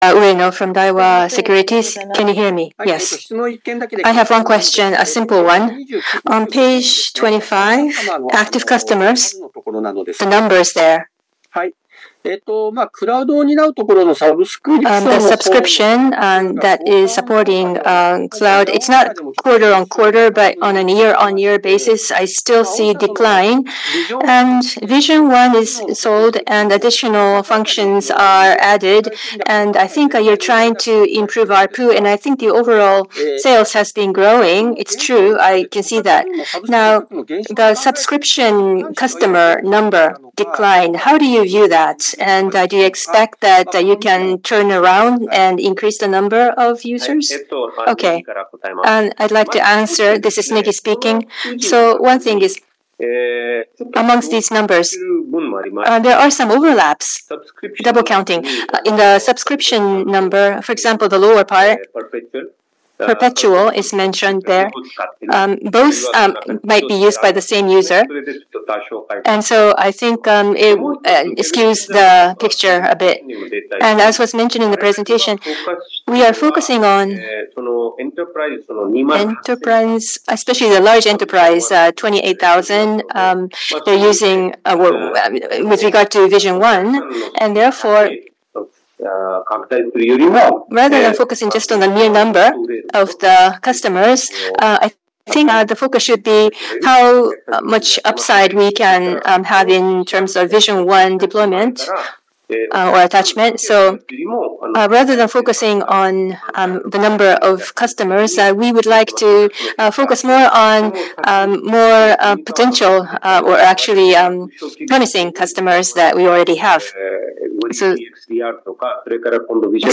Earnings Conference Audio and Video